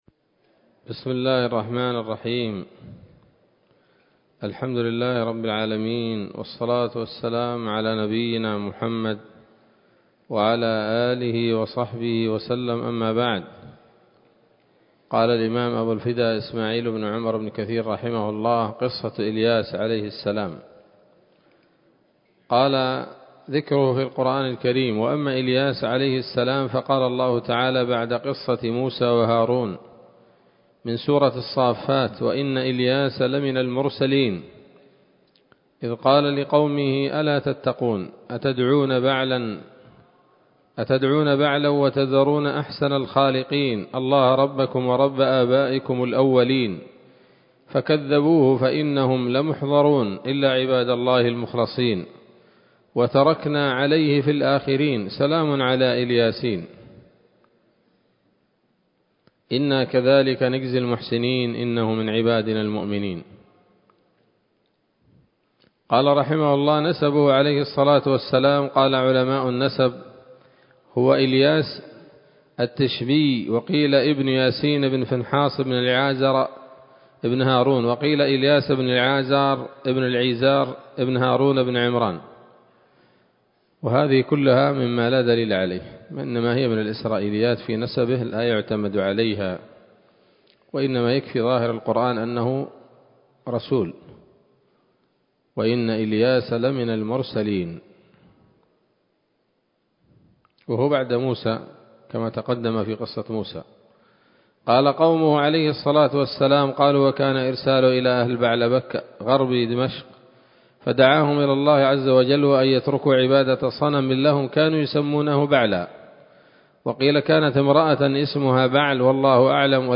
‌‌الدرس الرابع عشر بعد المائة من قصص الأنبياء لابن كثير رحمه الله تعالى